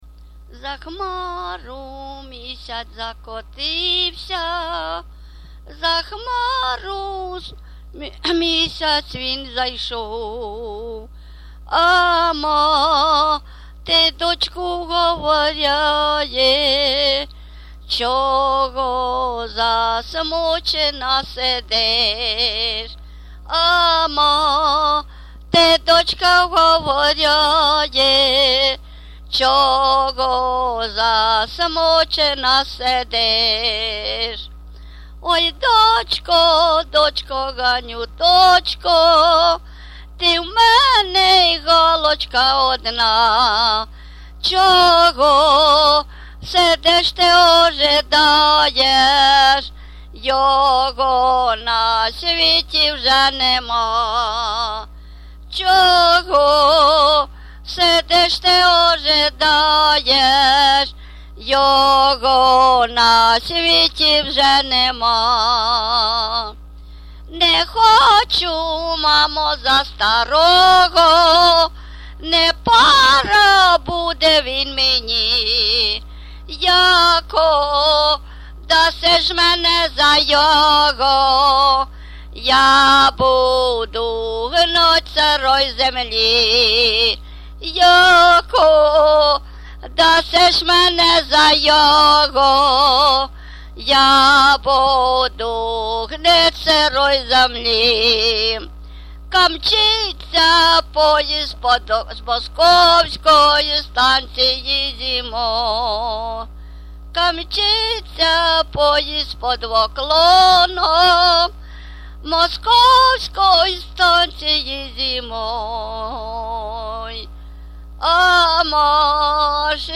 ЖанрПісні з особистого та родинного життя, Балади
Місце записус. Нижні Рівні, Чутівський район, Полтавська обл., Україна, Слобожанщина